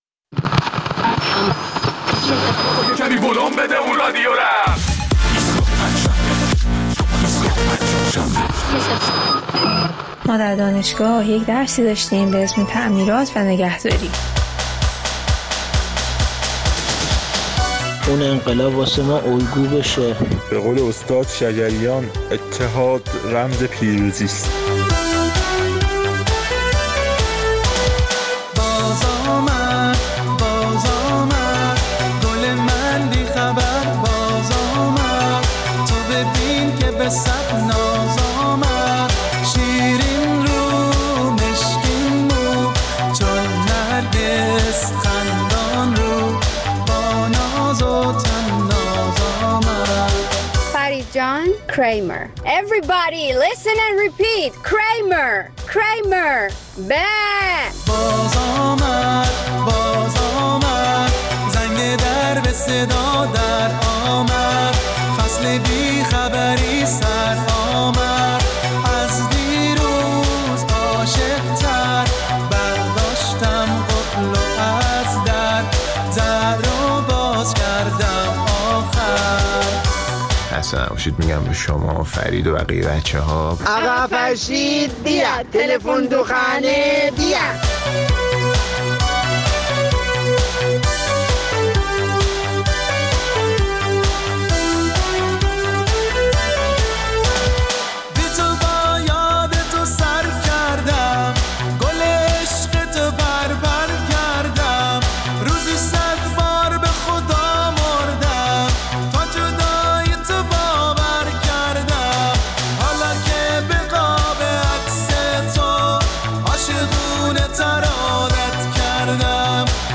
در این برنامه ادامه نظرات شنوندگان را در مورد ریشه‌یابی ناآرامی‌های اخیر در شهرهای مختلف کشور می‌شنویم.